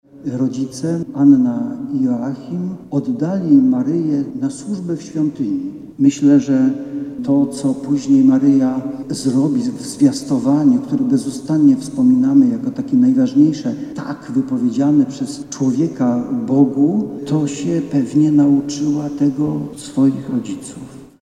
W kościele akademickim świętej Anny w Warszawie w niedzielę odprawiono mszę świętą odpustową.